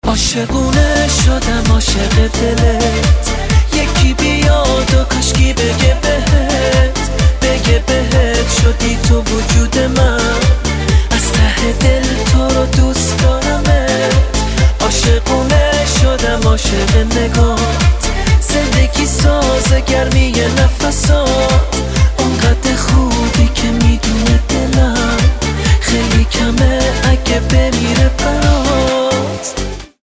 رینگتون پرانرژی و باکلام